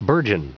Prononciation du mot burgeon en anglais (fichier audio)
Prononciation du mot : burgeon